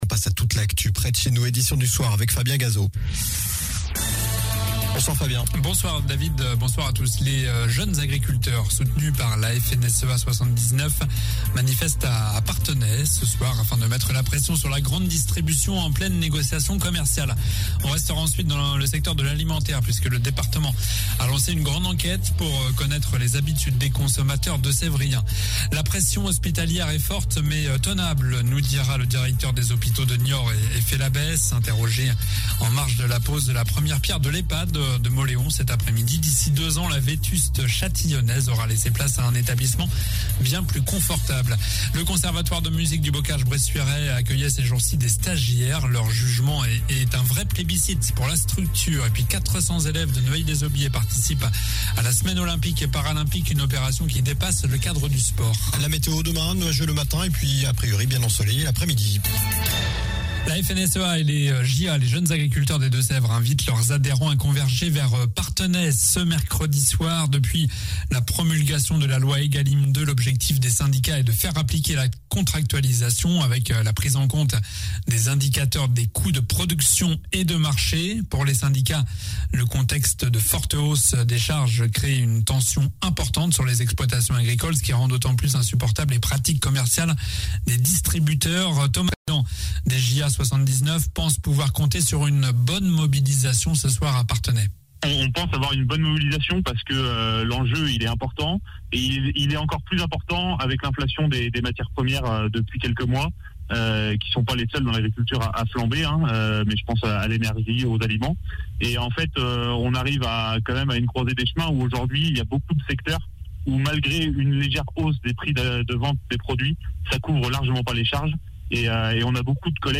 Journal du mercredi 26 janvier (soir)